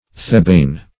Search Result for " thebaine" : The Collaborative International Dictionary of English v.0.48: Thebaine \The*ba"ine\, n. [So called from a kind of Egyptian opium produced at Thebes.]
thebaine.mp3